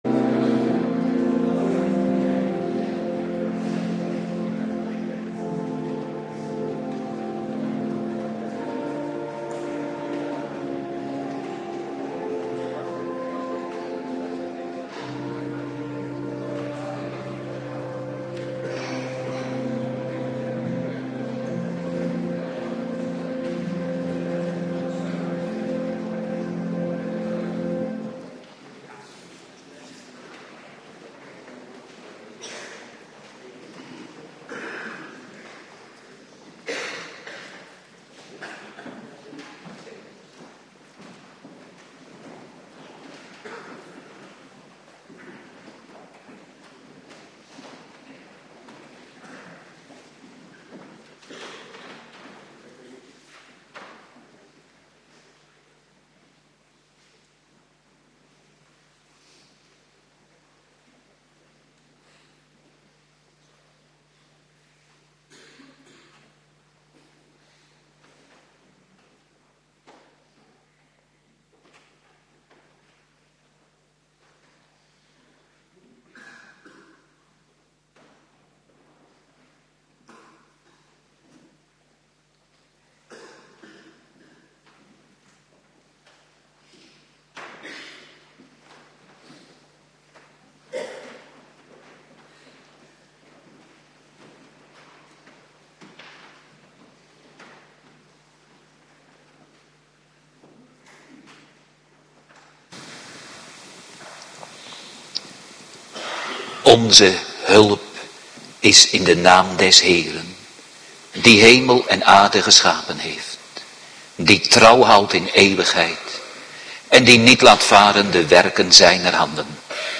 Avonddienst